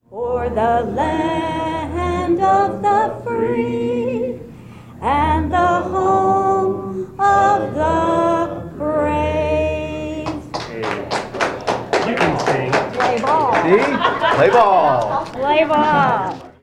Representative Cahill Sings at Iowa Capitol
Yesterday, Sue Cahill, Representative in the Iowa House from Marshall County, led Iowa lawmakers in singing of the National Anthem.
Cahill broke out in song when a bill that was proposed would have students to sing the national anthem every day in school.  While Cahill led the representatives in song, she opposed the bill.